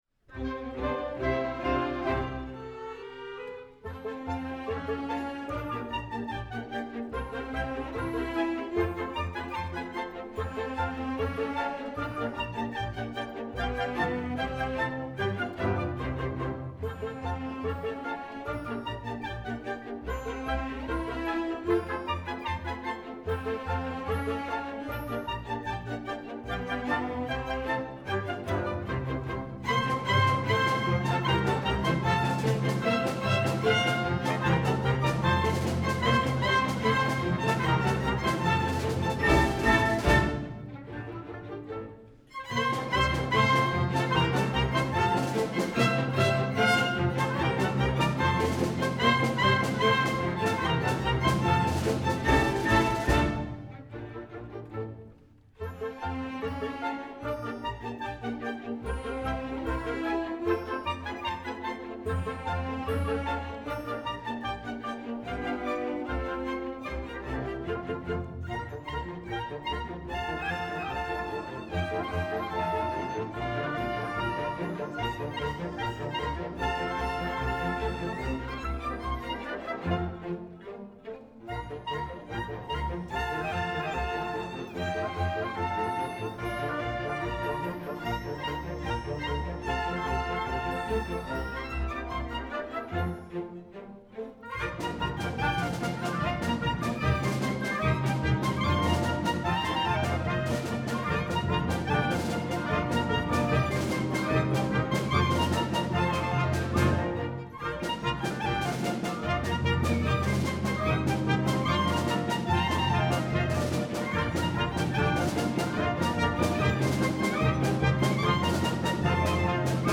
Concert du nouvel an 2026
(Wav stéréo 48Khz 24Bits non compressé, nécessite un haut-débit)